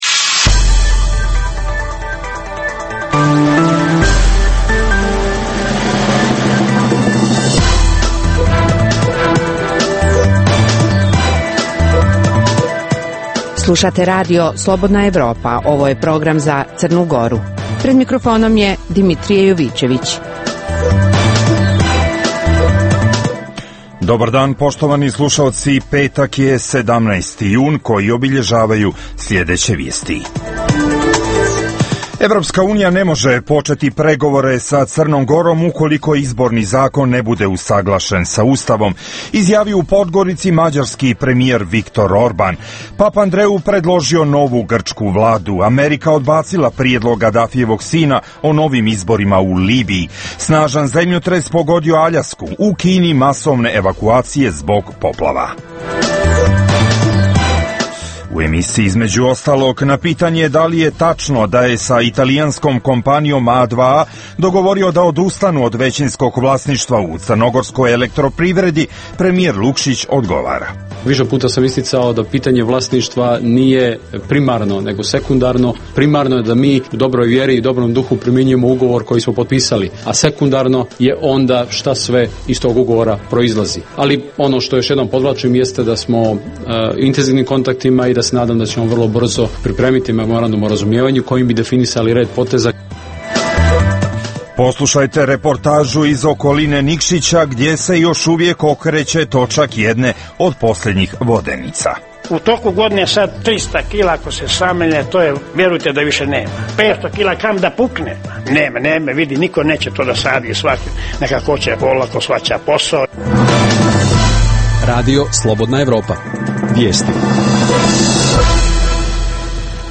- Da li je tačno da je premijer Lukšić sa italijanskom kompanijom A2A dogovorio da odustanu od većinskog vlasništva u crnogorskoj Elektroprivredi - Poslušajte reportažu iz okoline Nikšića gdje se još uvijek okreće točak jedne od posljednih vodenica.